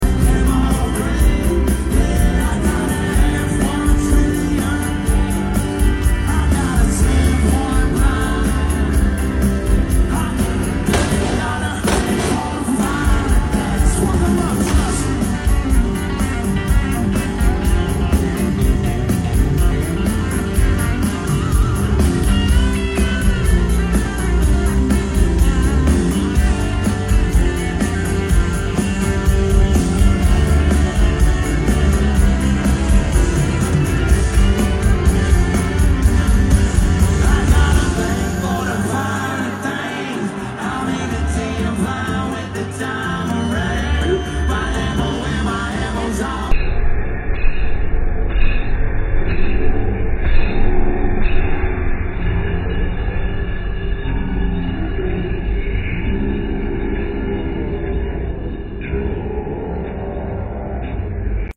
Post Malone slips and falls at his show in Orlando…